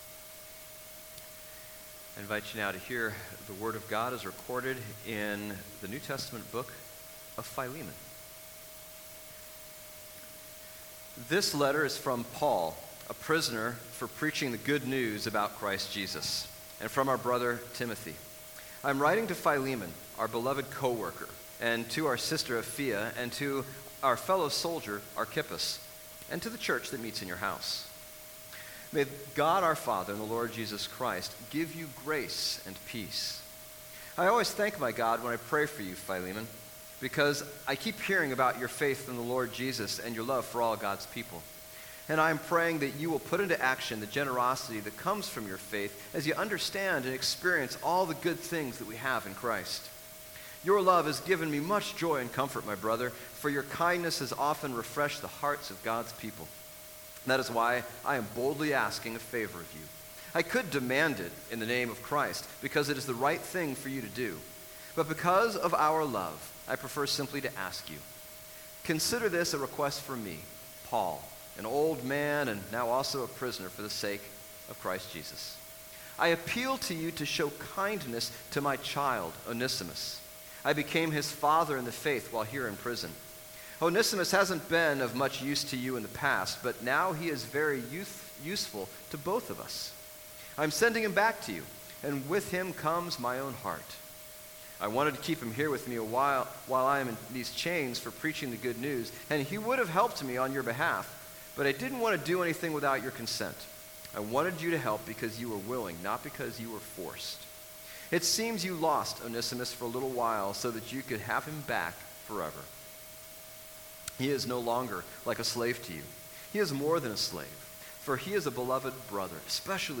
Bible Text: Philemon 1-25 | Preacher